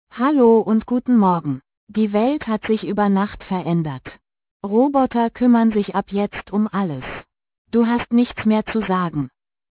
Es sind noch Zischlaute in den Soundfiles (WAV):